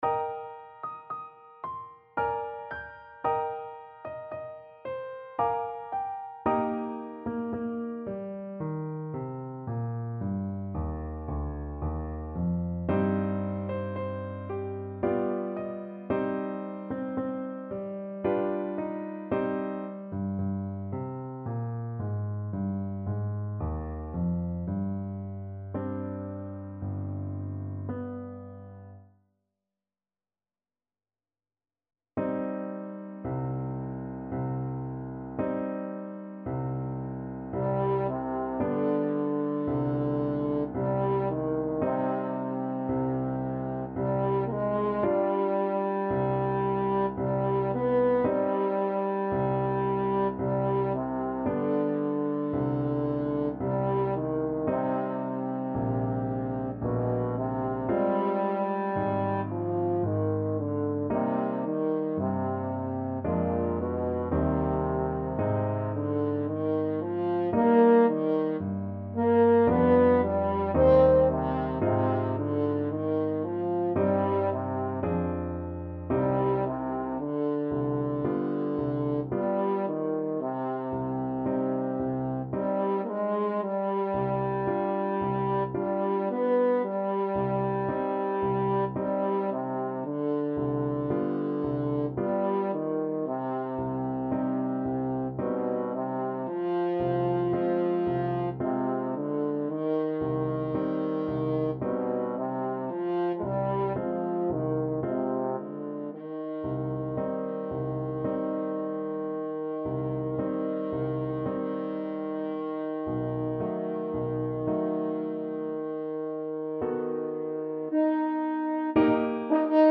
French Horn
Eb major (Sounding Pitch) Bb major (French Horn in F) (View more Eb major Music for French Horn )
3/4 (View more 3/4 Music)
~ = 56 Andante
Classical (View more Classical French Horn Music)
tchaik_serenade_melancolique_HN.mp3